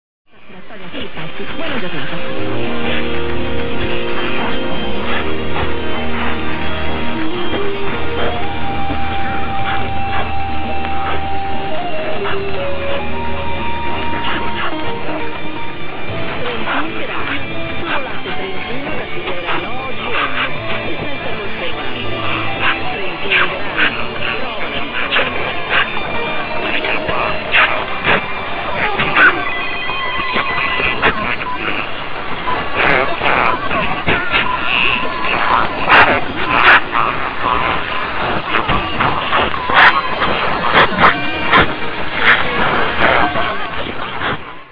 Loggings from Quoddy House [QH] near Lubec, ME